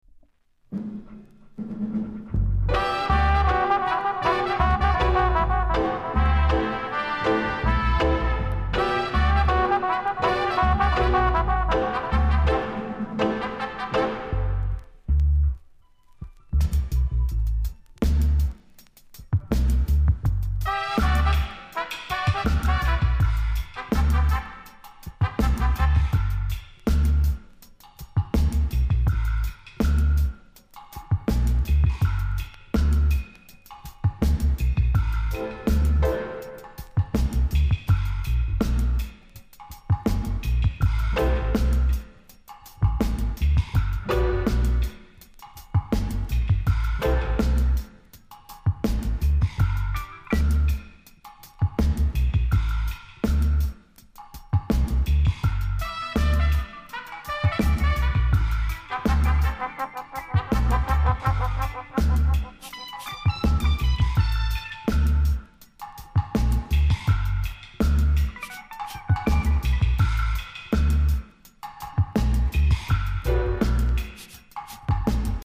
サイドB DUB
コメント LOVERS CLASSIC!!